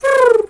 pigeon_2_emote_04.wav